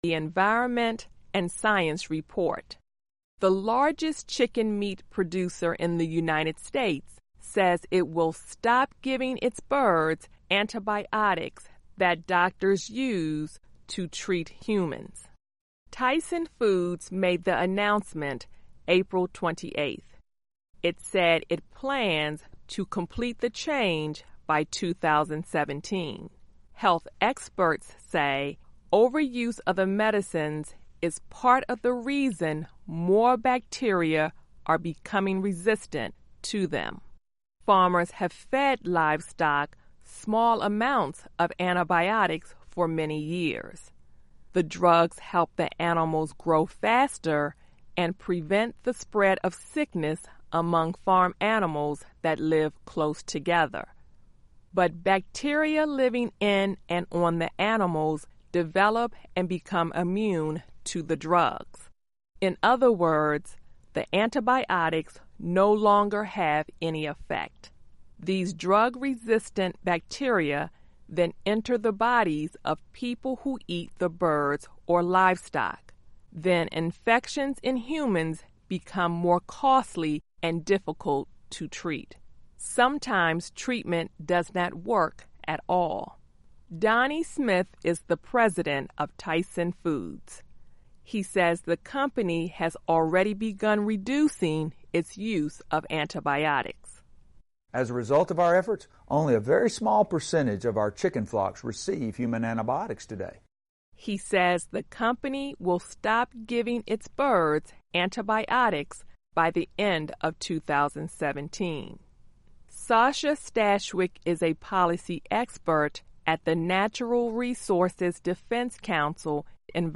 Learning English as you listen to a weekly show about the environment, science, farming, food security, gardening and other subjects. Our daily stories are written at the intermediate and upper-beginner level and are read one-third slower than regular VOA English.